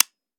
weap_romeo870_disconnector_plr_05.ogg